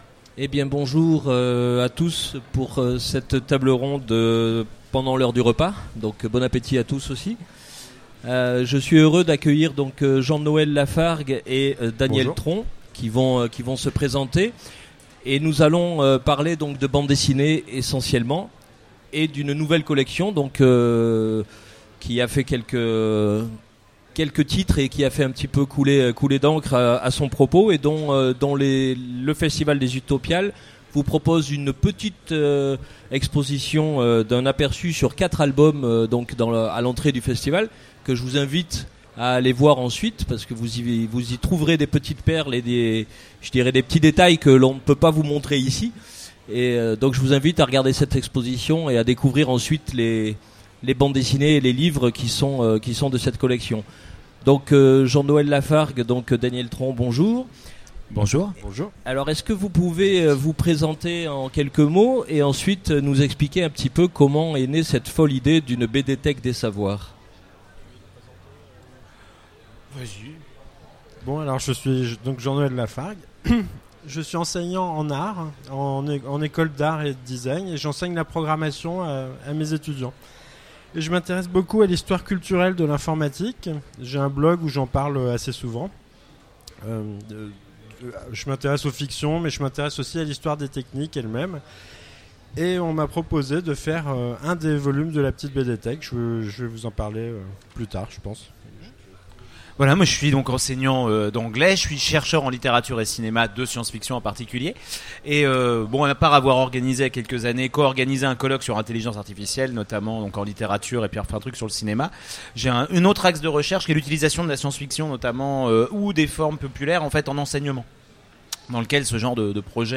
Utopiales 2016 : Conférence La petite bédéthèque des savoirs